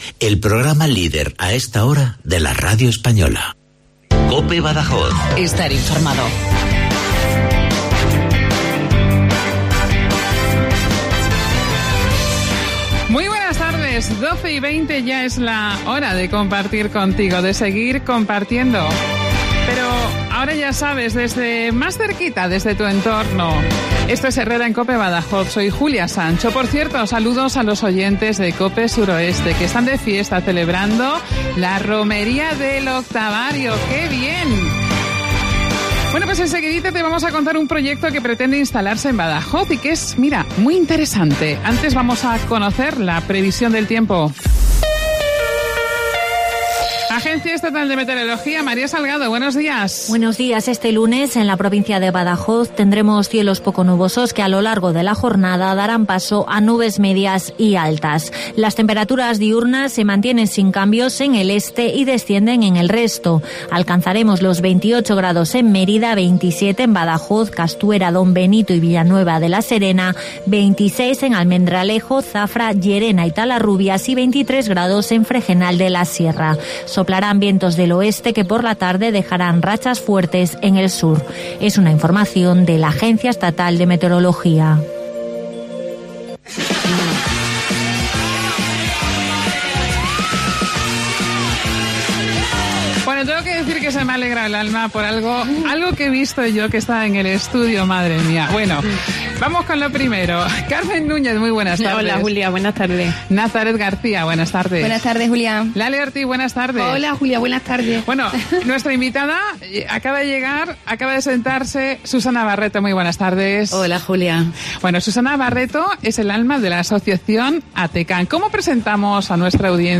Y ha venido con Uno, el perro cruce de mastín que utiliza para las terapias que tanto bien están haciendo.